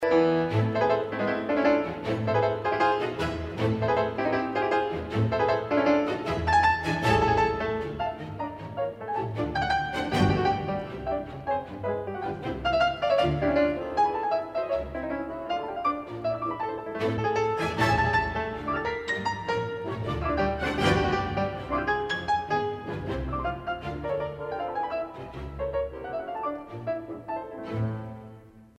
如前速，钢琴强有力变奏，弦乐与木管断奏伴奏